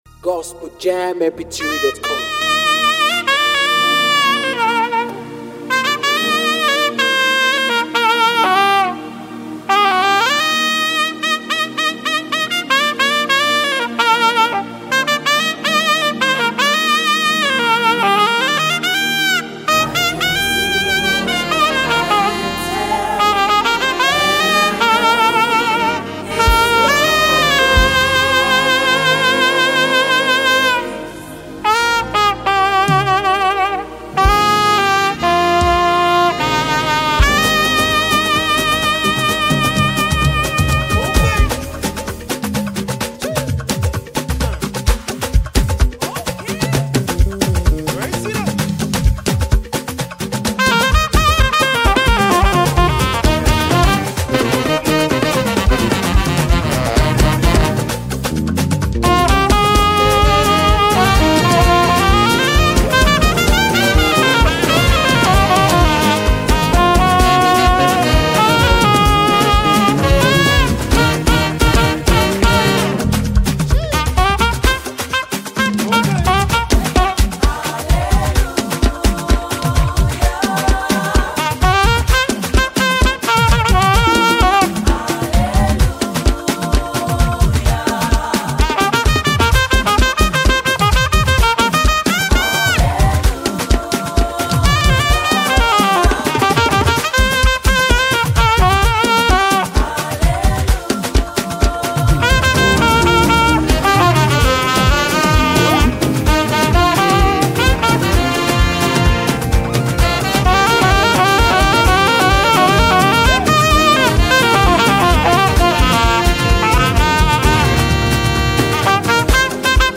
is a vibrant and spirit-lifting instrumental praise medley
Known for his mastery of the saxophone
Genre: Gospel / Instrumental Praise